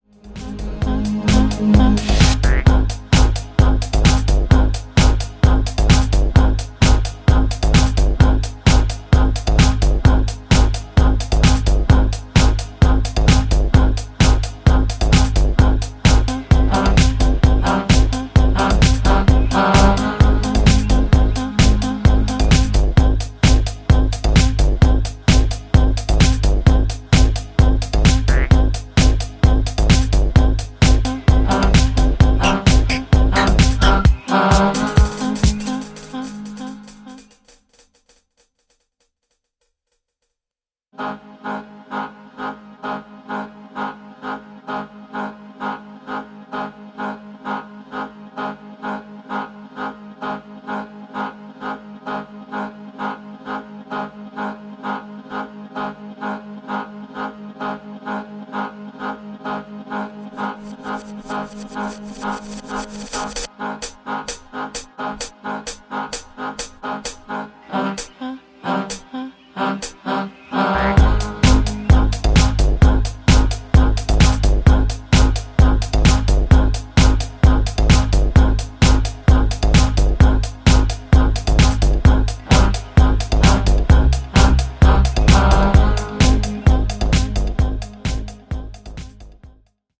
いずれも原曲の耳に残るヴォイス・フレーズを効果的に用いたモダン・ハウス・トラックに仕上がっていて全バージョン楽しめます！